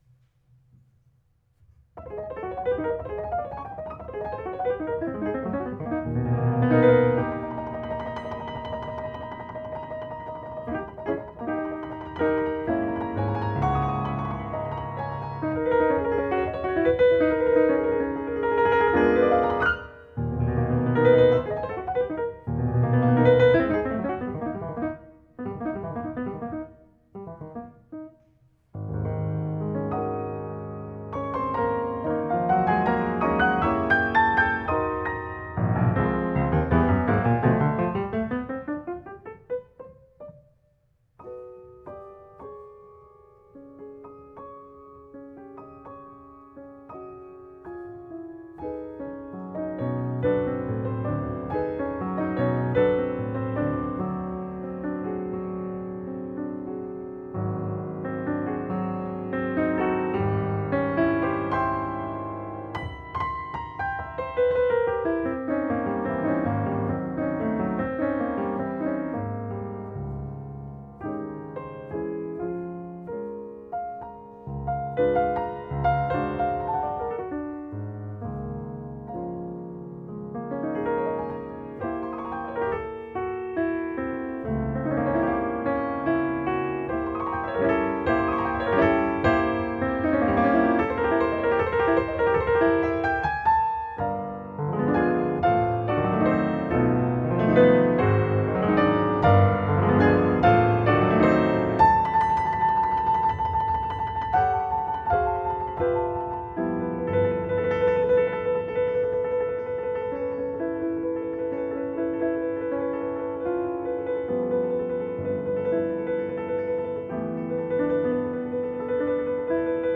피아노 소품집이면서도 다양한 작곡 기법 시도와 음악적 아름다움으로 드뷔시 후기 중요 작품으로 평가받는다.
스케르초-왈츠-스케르초의 3부 형식으로 구성된다.